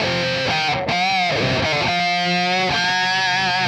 AM_RawkGuitar_130-C.wav